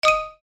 Open_Popup.mp3